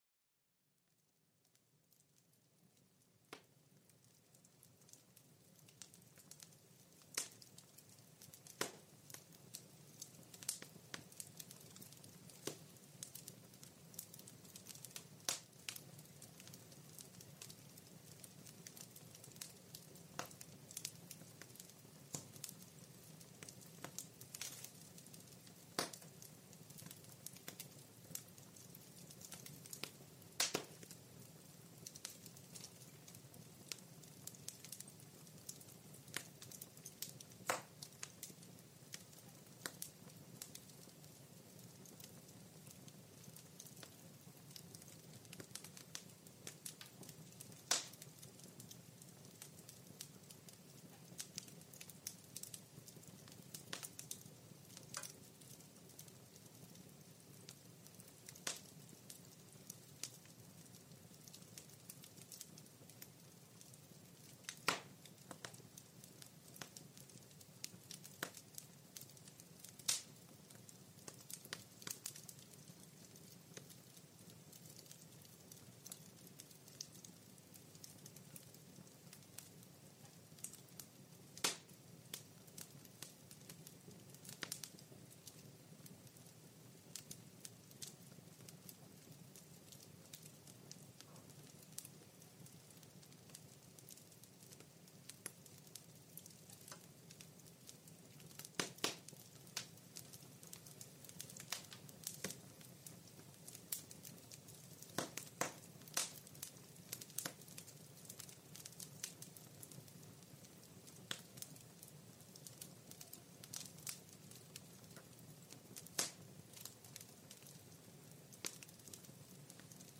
Son apaisant du feu - Plongez dans la chaleur réconfortante du crépitement des flammes pour calmer votre esprit
Cet épisode vous transporte au cœur d'un feu de camp, où le crépitement des flammes et les étincelles vous enveloppent d'une chaleur apaisante. Laissez-vous bercer par le son hypnotique du feu qui vous aidera à relâcher toutes les tensions de la journée.